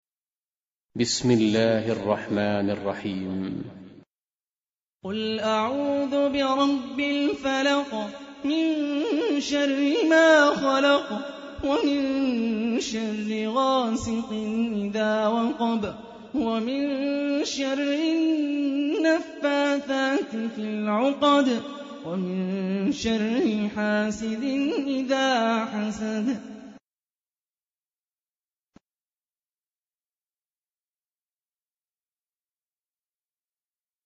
Қуръони карим тиловати, Қорилар. Суралар Qur’oni karim tilovati, Qorilar.